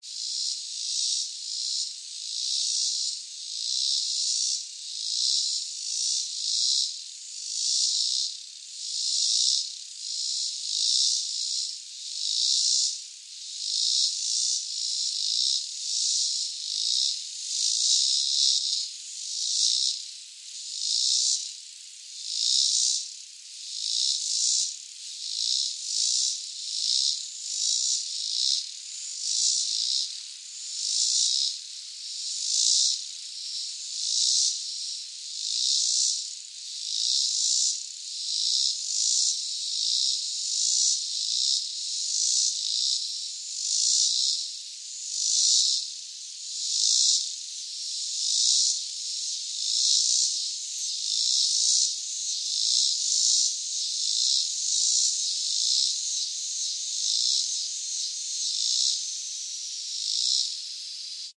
鸟类和蜜蜂 " 夏日蝉鸣
描述：今年夏天蝉似乎很响亮。在我的后门录制了一个Zoom H1。
Tag: 氛围 昆虫 性质 现场录音 噪音 夏天